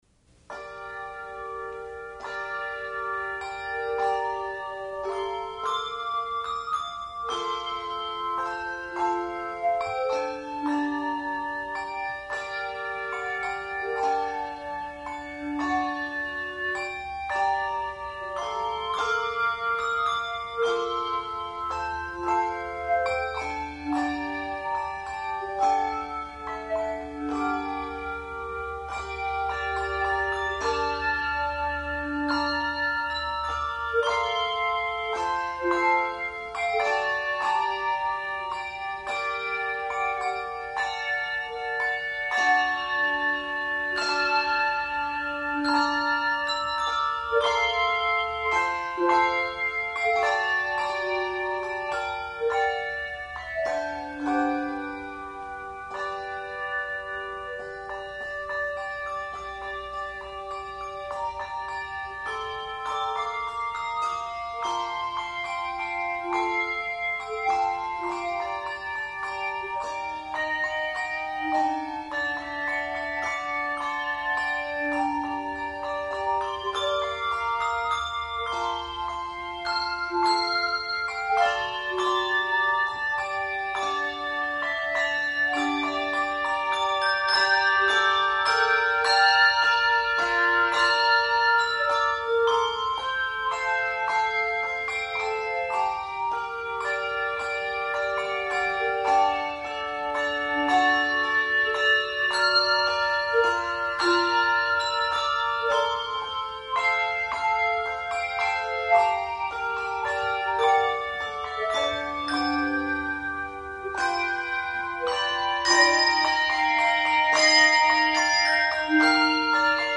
Handbell Quartet
A sensitive setting of a Christmas favorite.
Genre Sacred
No. Octaves 3 Octaves